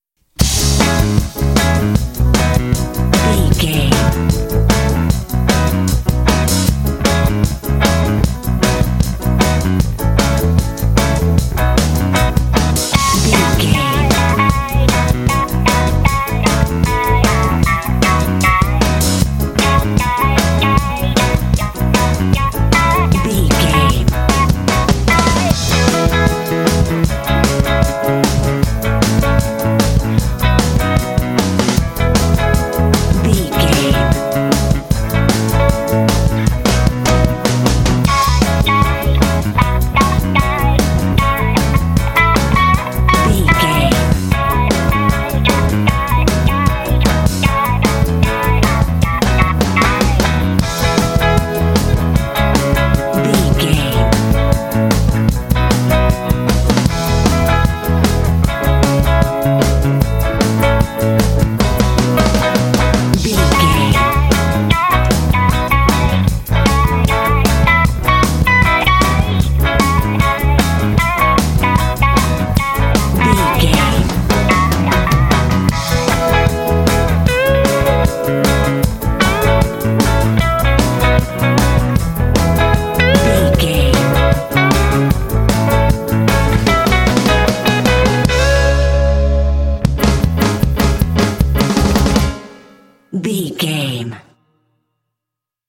Uplifting
Ionian/Major
funky
happy
bouncy
groovy
electric guitar
bass guitar
drums
piano
percussion
pop
upbeat